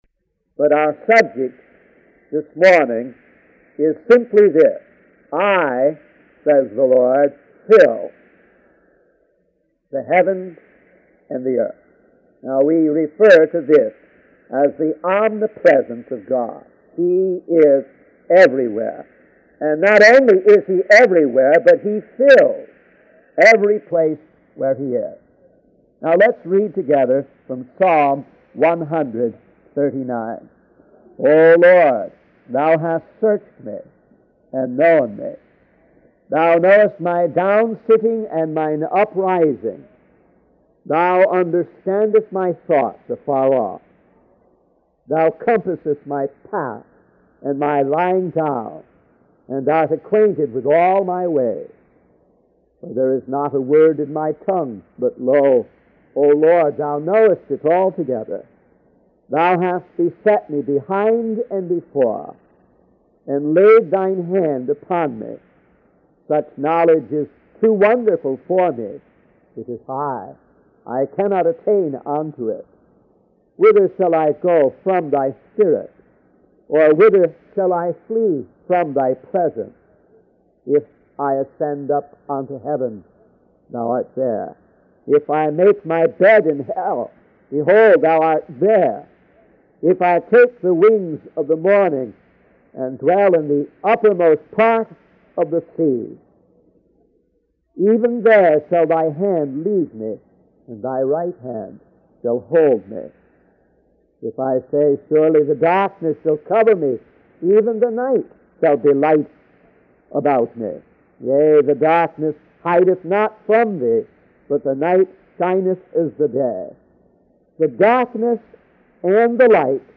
In this sermon, the preacher explores the concept of God's omnipresence and authority over all places. He emphasizes that while we are accustomed to things having their rightful place, God exists everywhere and cannot be escaped.